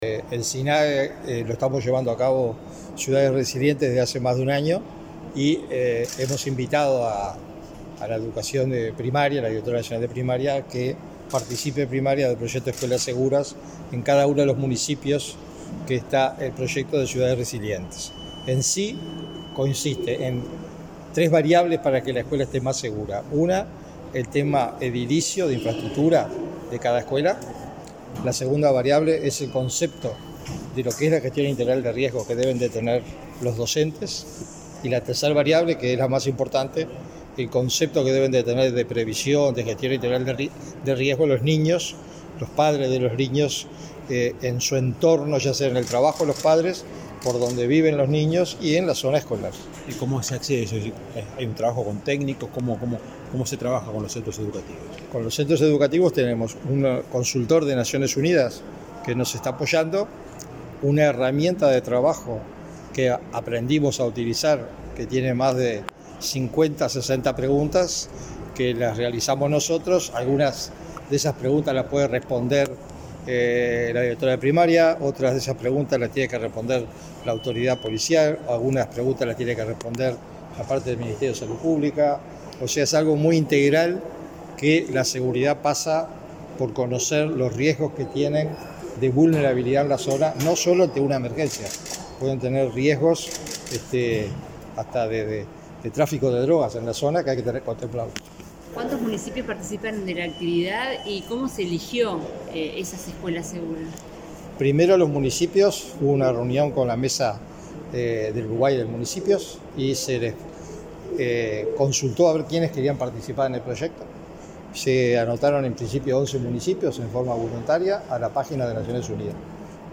Declaraciones del director del Sinae
El director del Sistema Nacional de Emergencias (Sinae), Sergio Rico, dialogó con la prensa luego de participar, en la Torre Ejecutiva, en el acto de